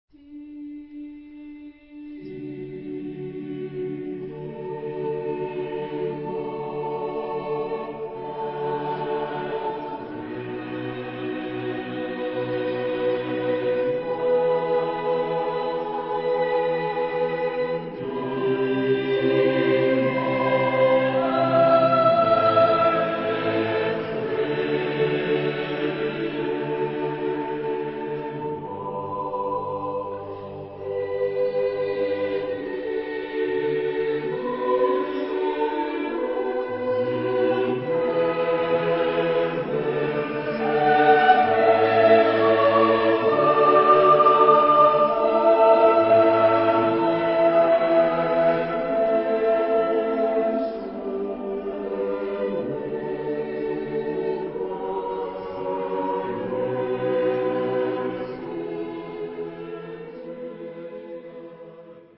Genre-Style-Forme : Sacré ; Renaissance ; Motet
Type de choeur : SSATBB  (6 voix mixtes )